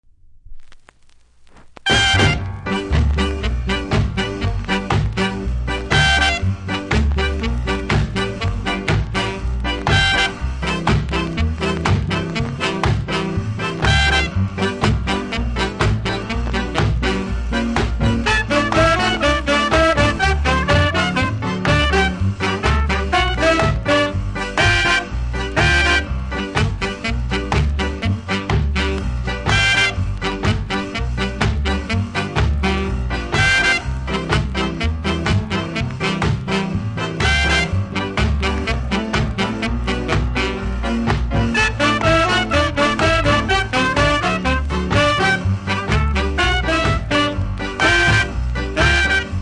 多少のノイズ気にならない方ならプレイ可レベル！